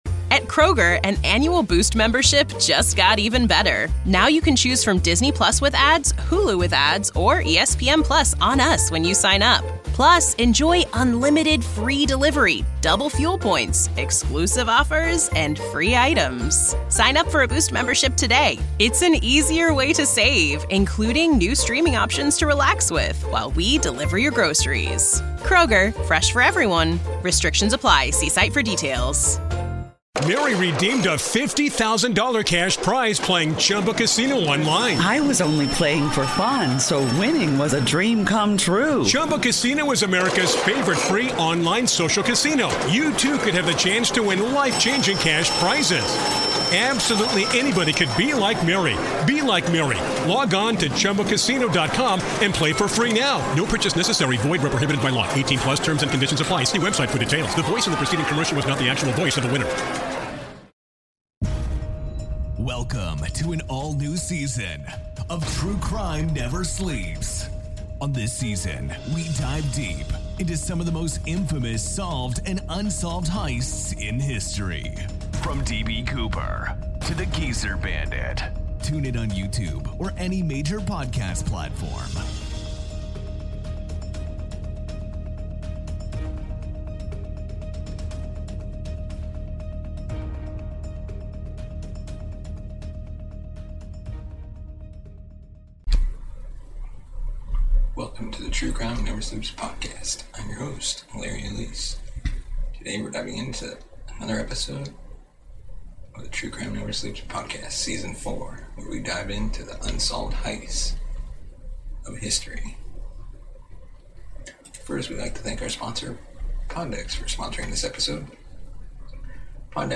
Equipment We Use From Amazon: USB Lava lier Lapel Microphone